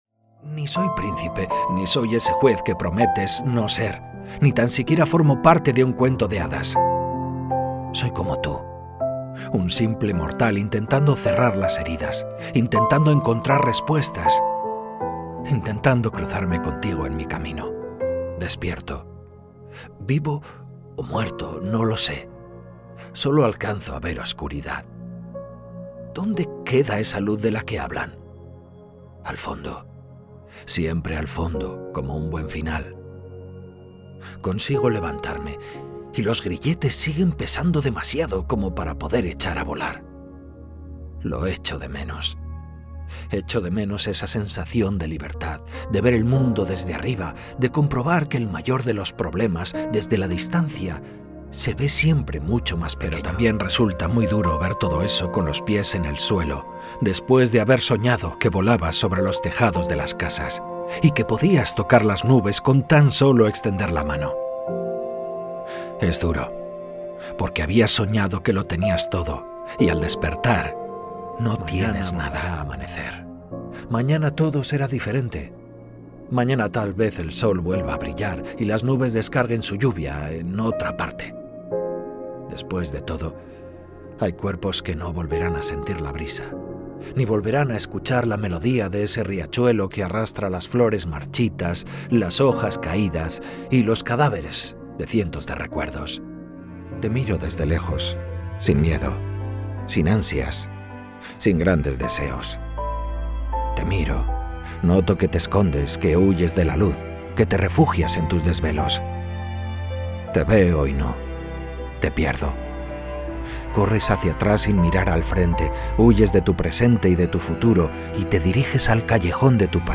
some of my jobs as online castilian spanish and basque voiceover
05Audiolibros con música Castellano